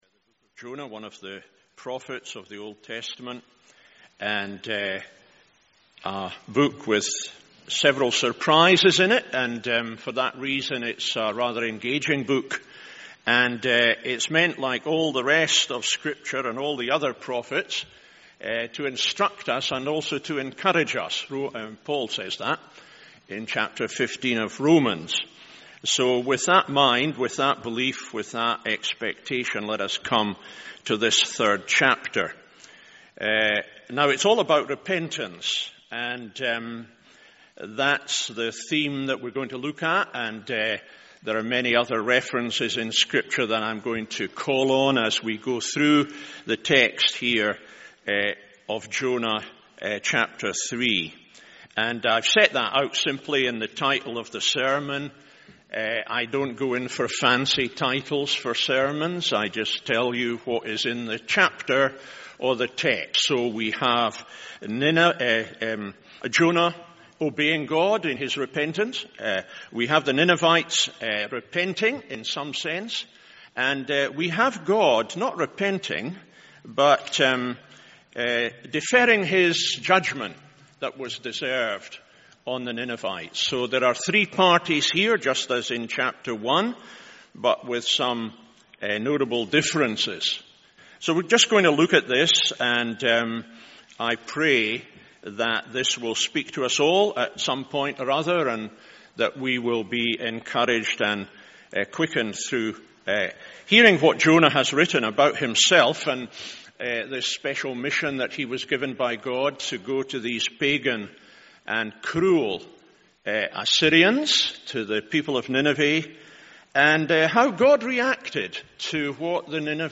MORNING SERVICE Jonah 3…